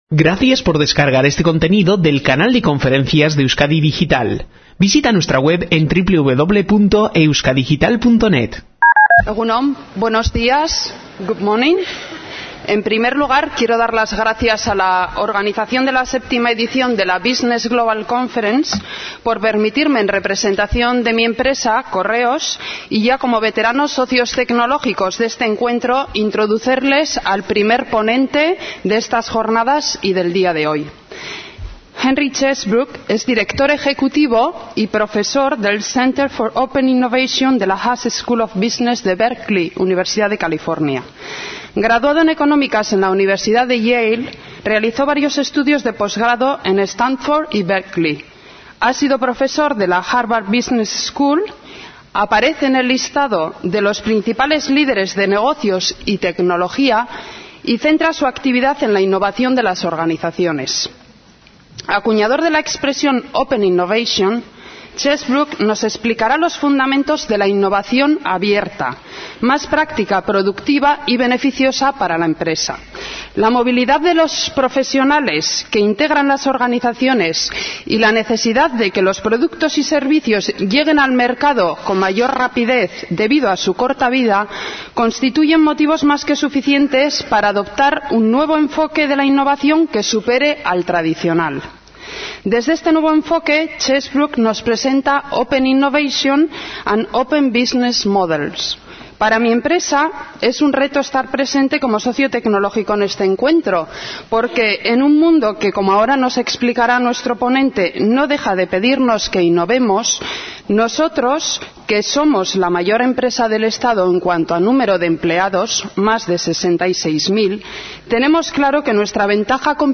Lo explica muy bien Eric Von Hippel, profesor del Innovation and Entrepreneurship Group en la Sloan School of Management del MIT. en su Conferencia dictada en Bilbao el pasado 22 de octubre de 2008 dentro del Bussiness Global Conference.